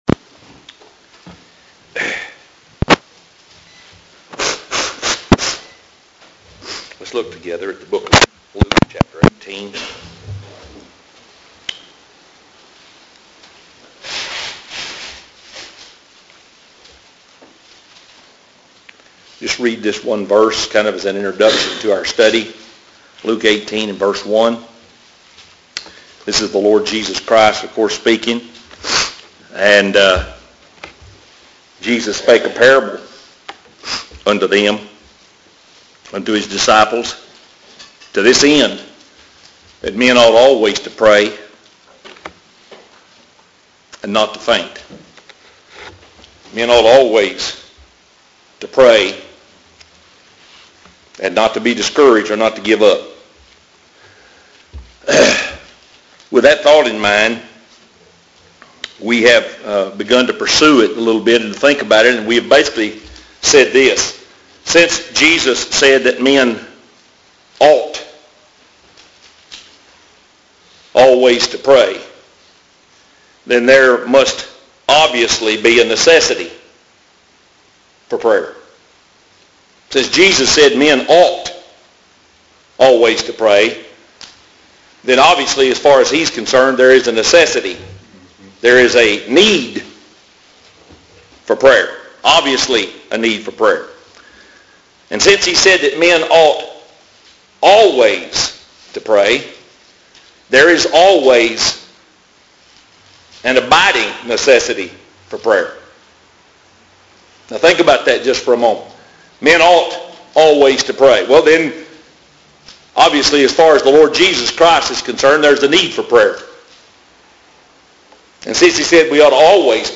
Audio Sermons | Baptist Standard Bearer, Inc.